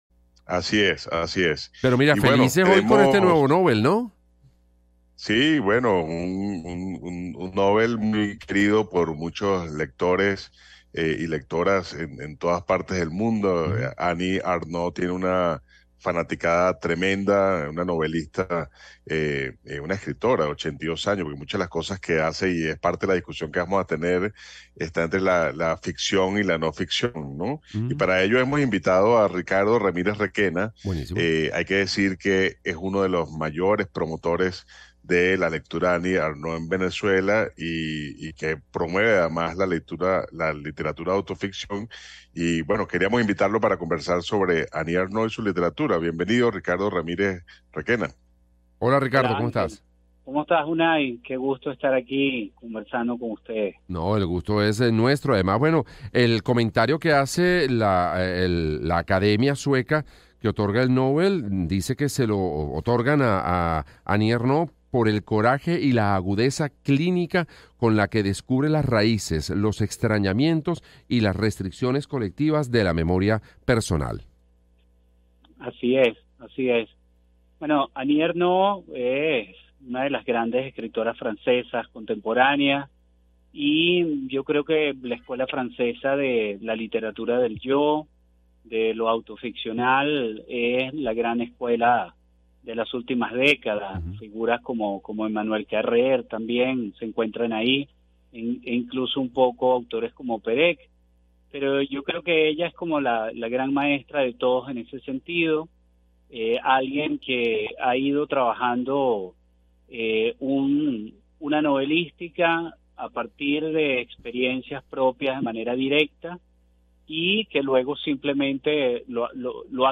El programa fue transmitido a través de todas las emisoras del Circuito Éxitos de Unión Radio.